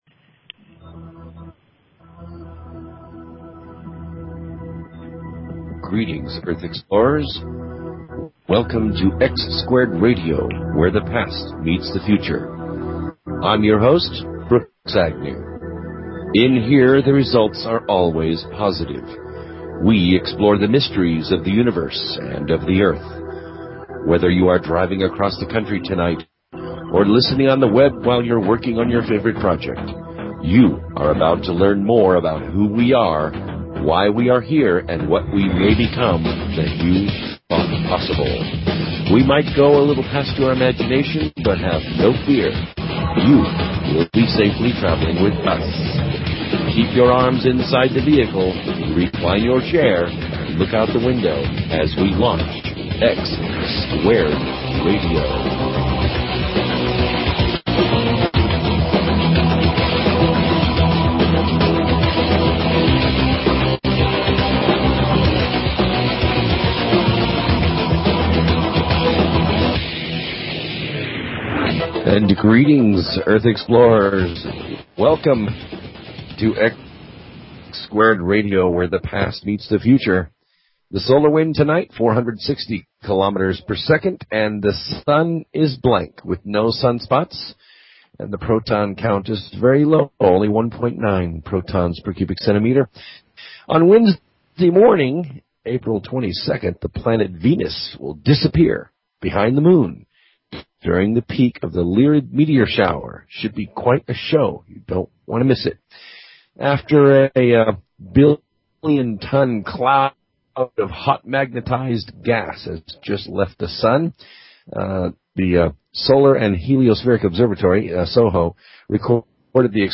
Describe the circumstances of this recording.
The studio was struck by lightning to start off the program...really great program.